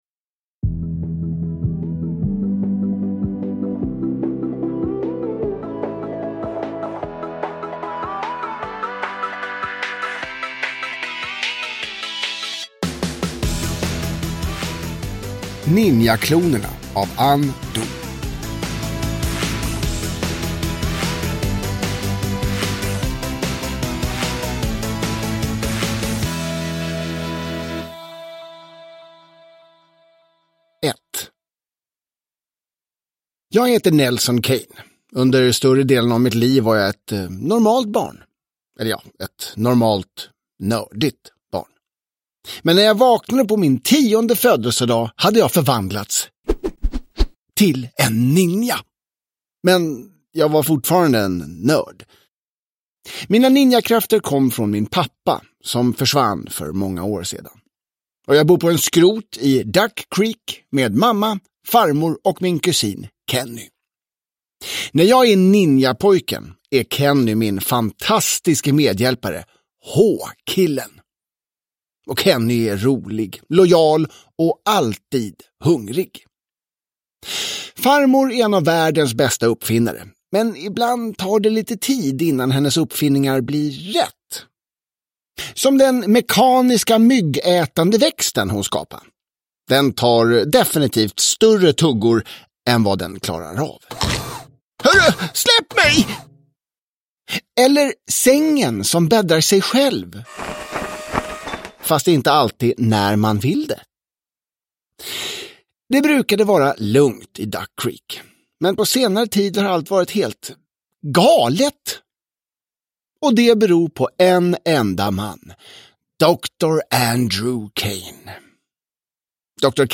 Ninjaklonerna – Ljudbok – Laddas ner